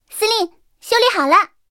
三号修理完成提醒语音.OGG